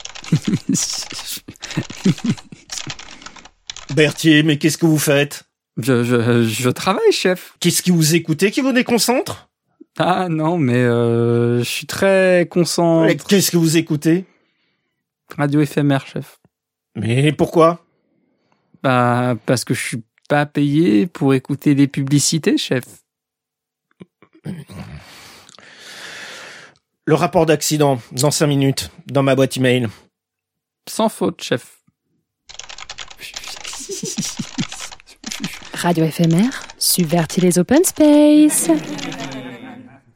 Jingle station : FMR, subvertit les open-spaces
[bruits de tapotements et de petits rires, petite conversation entendue dans un casque]